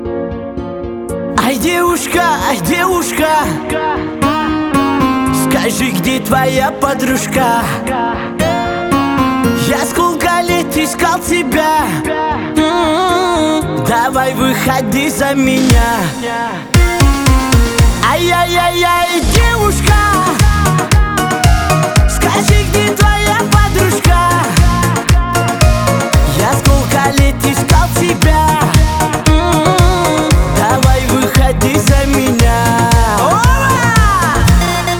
Жанр: Поп / Русские